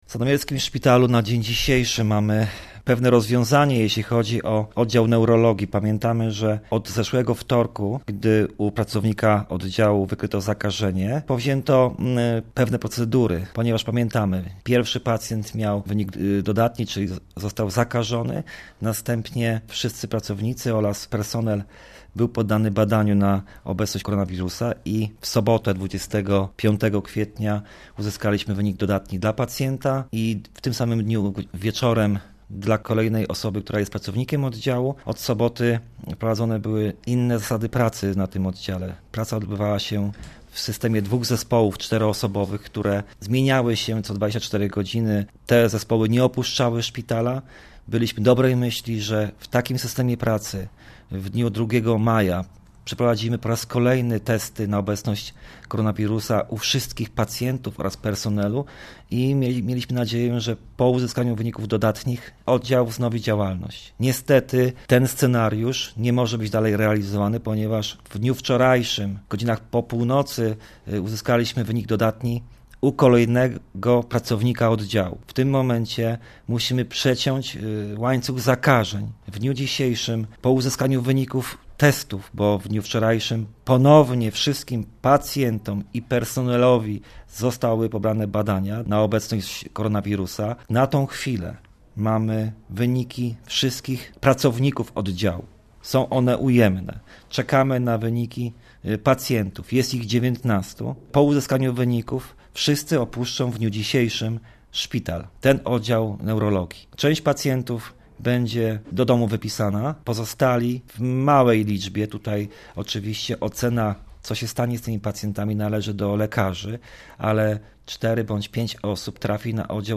Rozmowa ze starostą powiatu sandomierskiego Marcinem Piwnikiem o aktualnej sytuacji w Szpitalu Specjalistycznym Ducha Św. w Sandomierzu, gdzie potwierdzono 4 przypadki zakażenia koronawirusem.